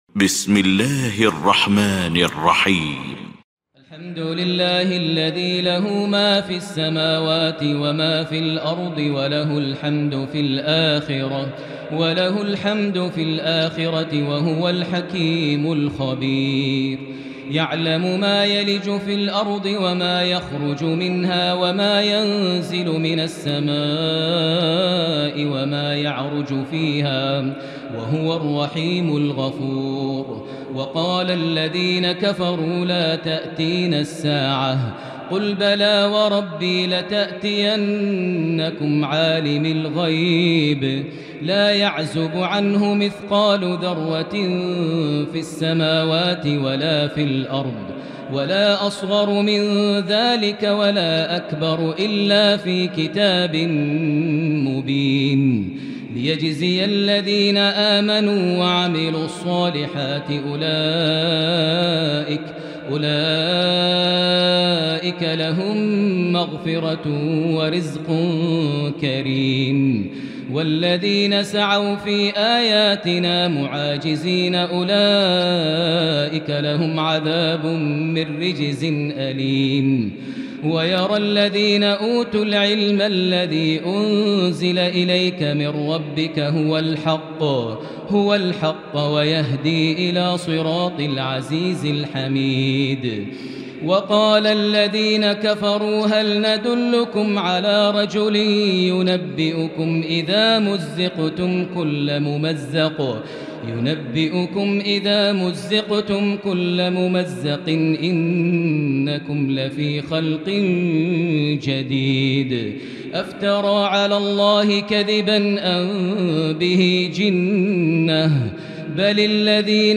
المكان: المسجد الحرام الشيخ: سعود الشريم سعود الشريم فضيلة الشيخ ماهر المعيقلي سبأ